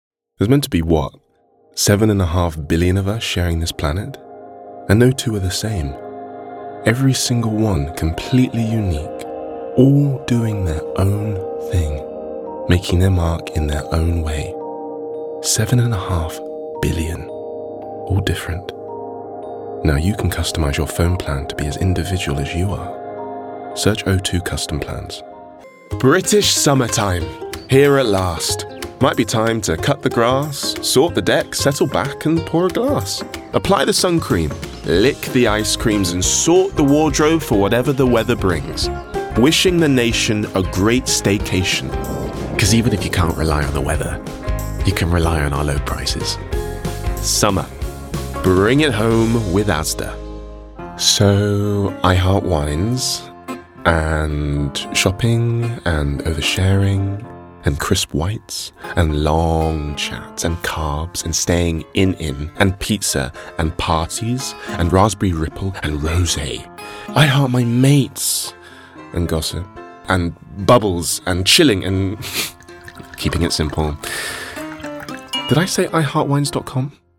20s-30s. Deep-tone. A collected and silky voice with plenty of humour and warmth. RP.
Commercials